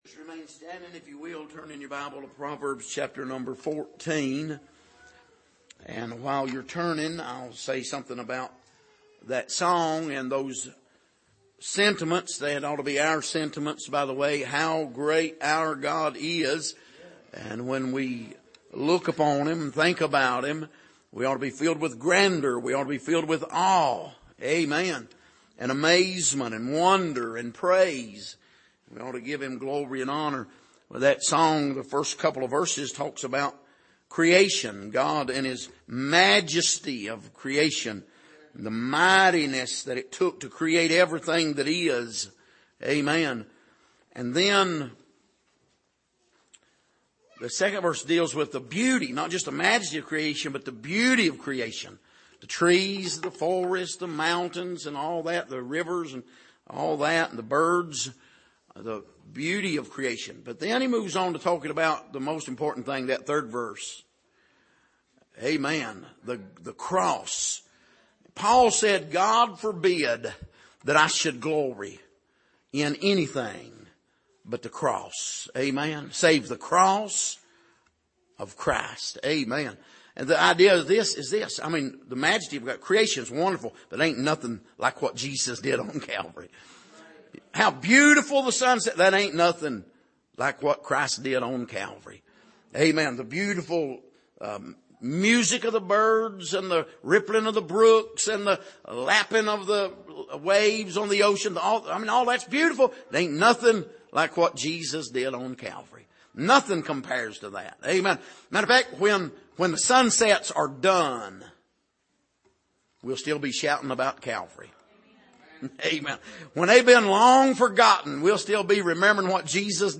Passage: Proverbs 14:28-35 Service: Sunday Evening